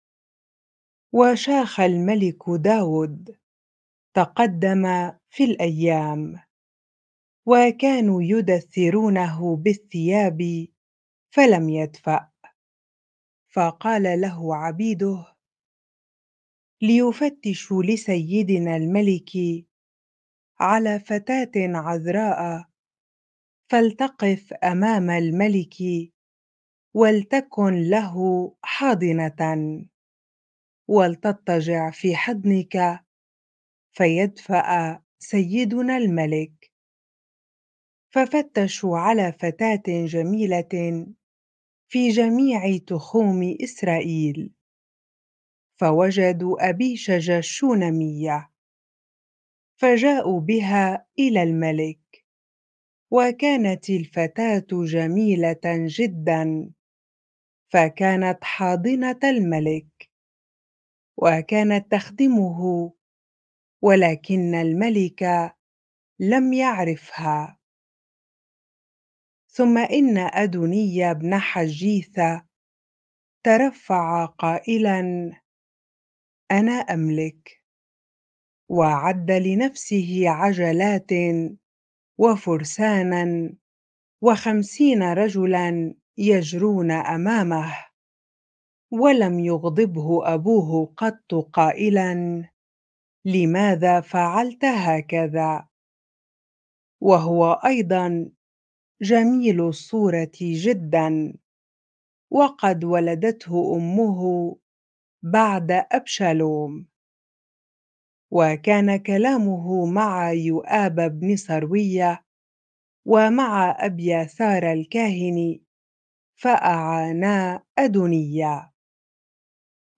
bible-reading-1 Kings 1 ar